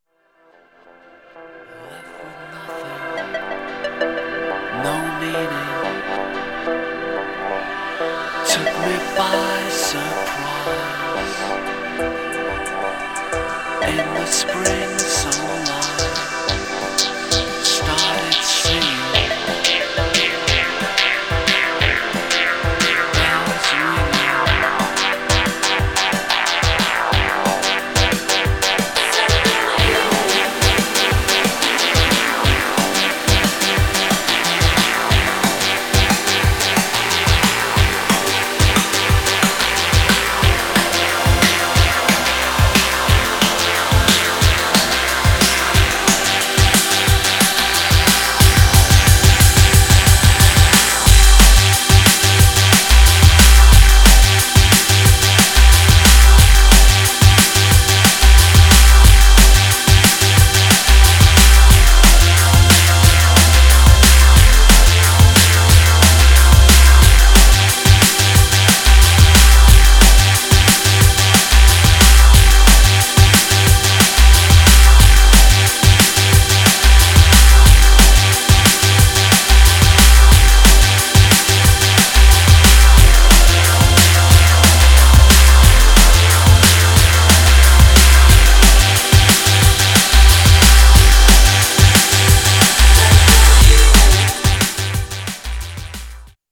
Styl: Dub/Dubstep, Drum'n'bass, Breaks/Breakbeat